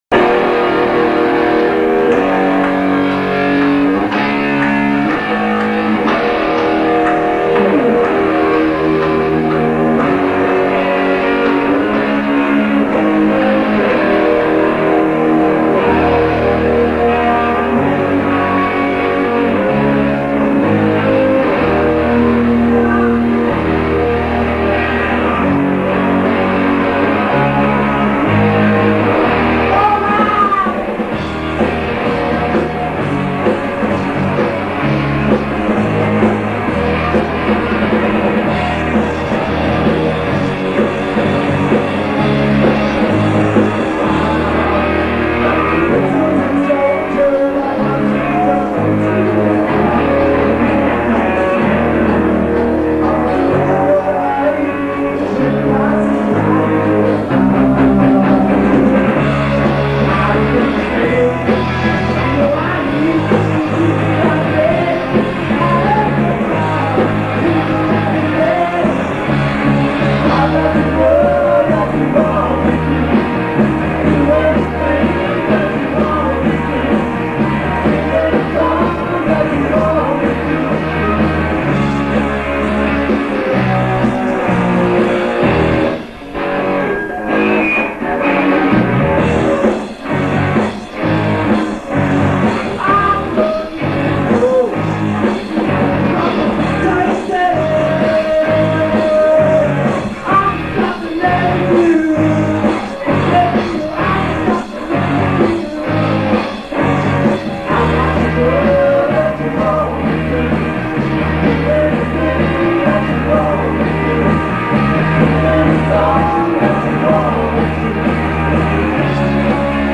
Regent Hotel Kingsford (1979)